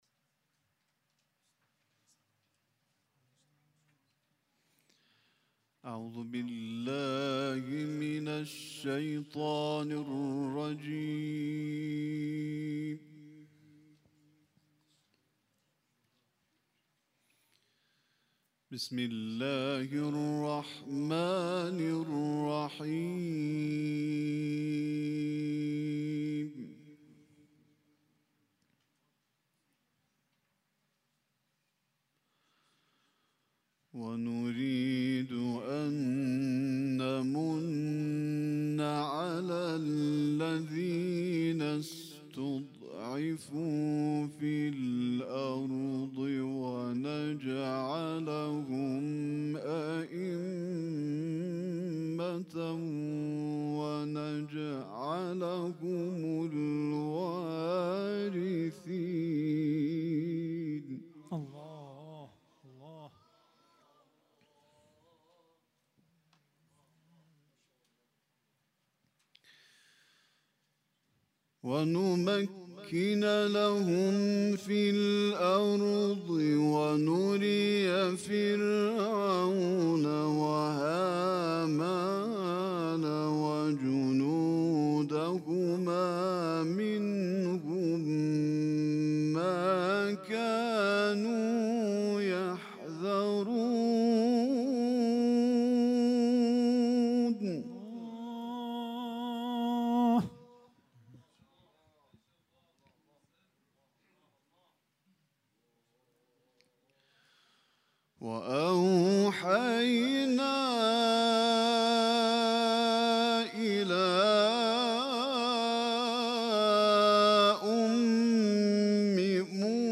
ایکنا _د «منادي» نومی لړۍ ۴۹محفل د اسلامشهر د قرآن‌پالو د ټولنې په هڅو، د دفاع مقدس د اونۍ د درناوي او د شهیدانو د یاد په پار جوړ شو